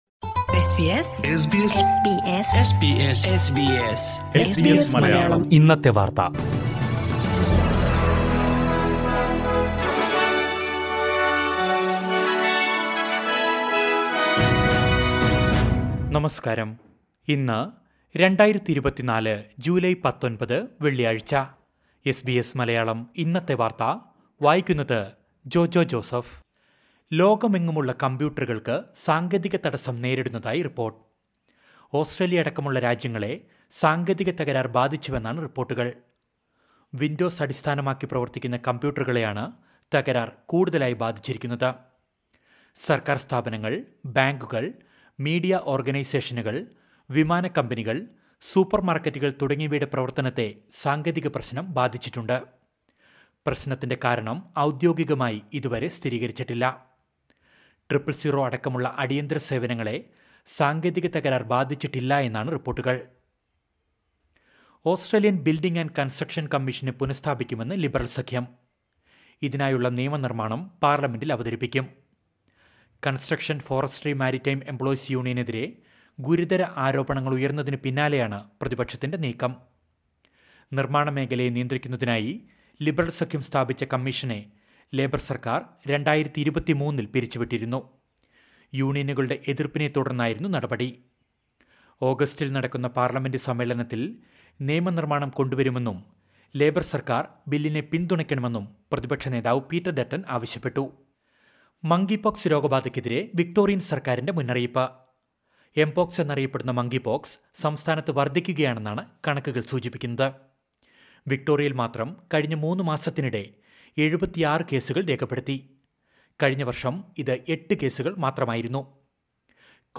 2024 ജൂലൈ 19ലെ ഓസ്‌ട്രേലിയയിലെ ഏറ്റവും പ്രധാന വാര്‍ത്തകള്‍ കേള്‍ക്കാം...